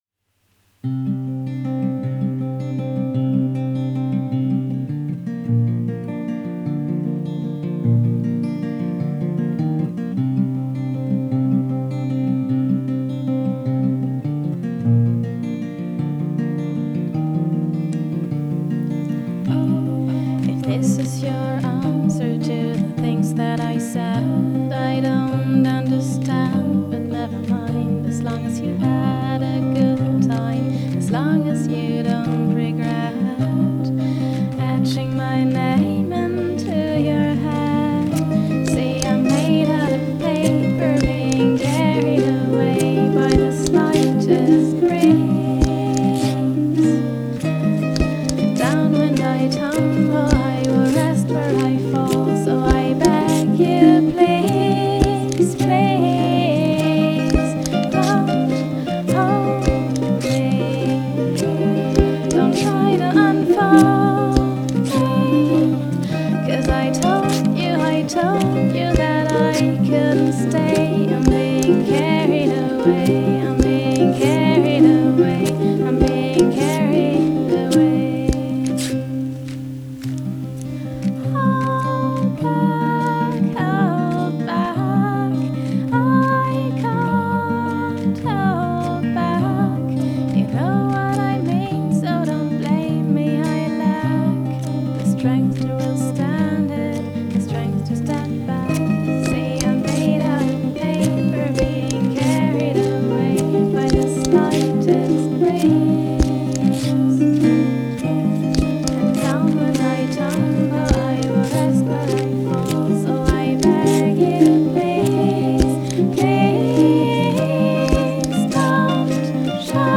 tout en douceur et en émotion.